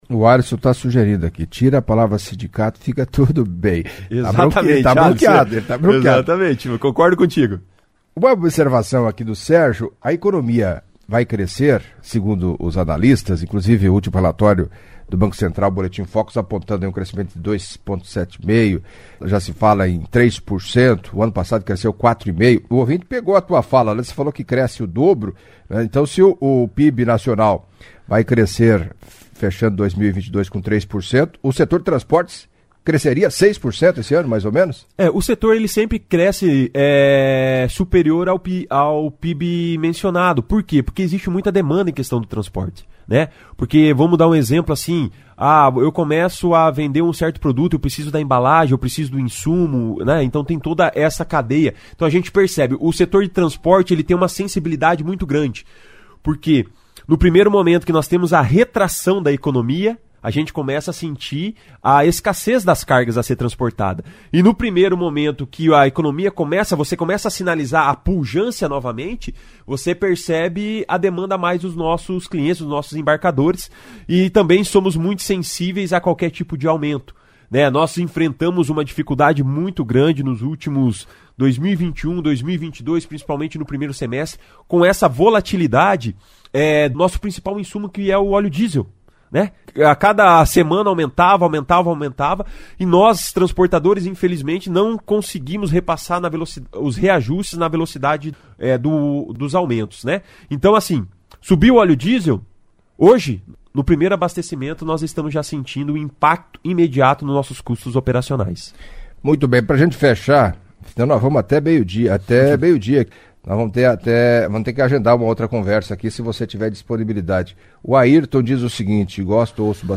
Em entrevista à CBN Cascavel nesta quarta-feira (02)